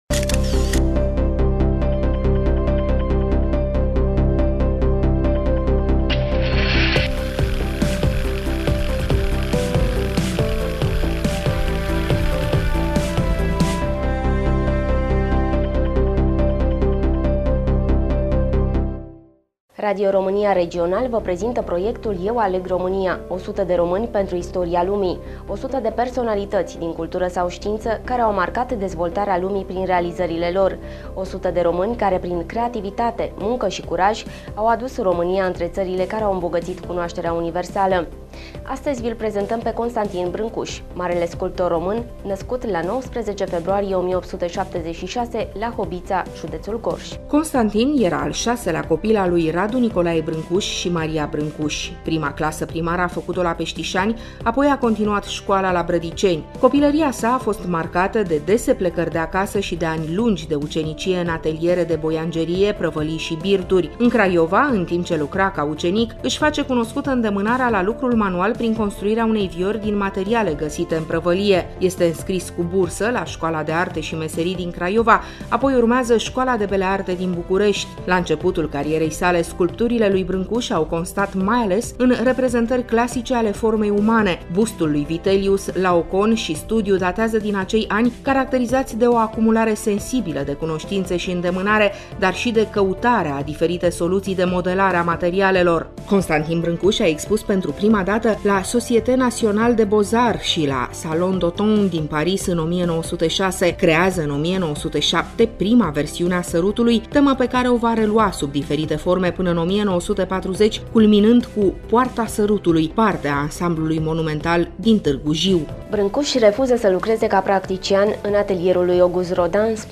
Studioul: RADIO ROMÂNIA CRAIOVA